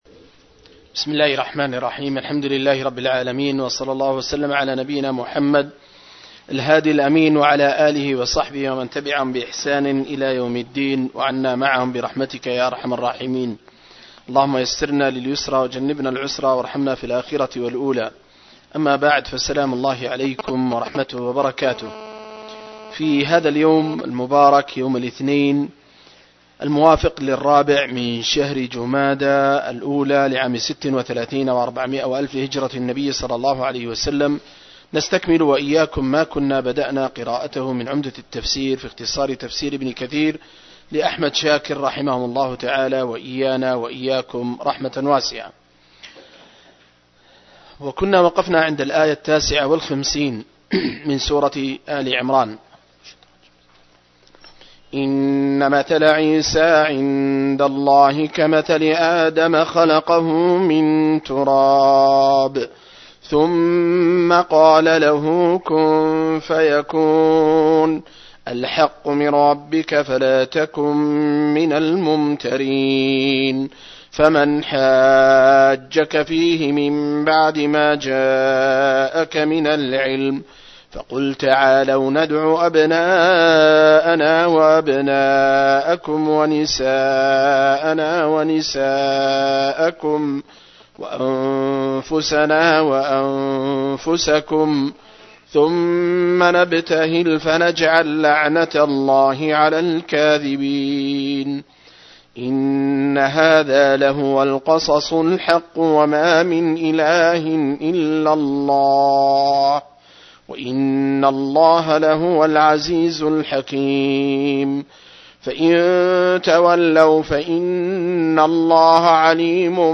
067- عمدة التفسير عن الحافظ ابن كثير رحمه الله للعلامة أحمد شاكر رحمه الله – قراءة وتعليق –